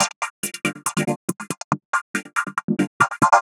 tx_synth_140_trickychops1.wav